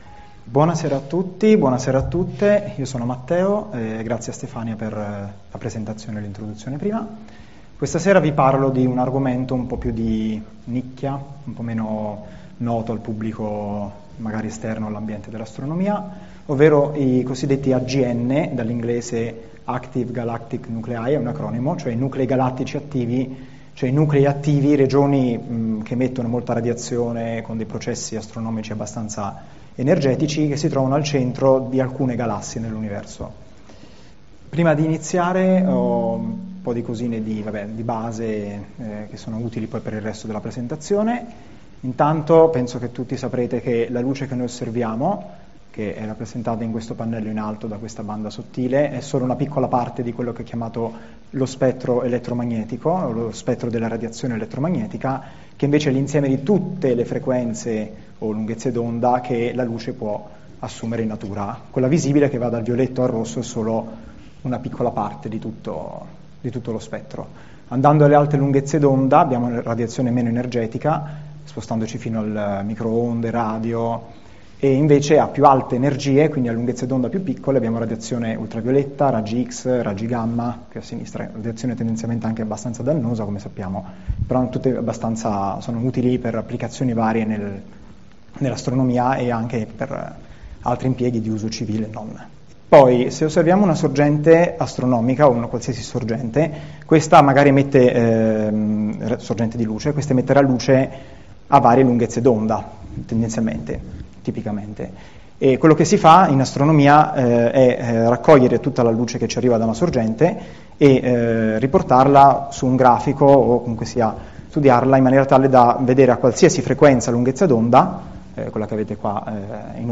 Sono infatti considerati sia un laboratorio unico per indagare le leggi della Fisica in condizioni non riproducibili in laboratorio, sia dei fari cosmici che permettono di studiare fenomeni dovuti alla geometria dello spazio-tempo fino ai confini dell’Universo osservabile. Sotto la volta stellata del Planetario di Milano sarà ripercorsa la storia della scoperta dei Nuclei Galattici Attivi, dalla identificazione dei primi Quasar negli anni ‘60 fino all’osservazione della loro emissione nei raggi X e Gamma con i telescopi spaziali.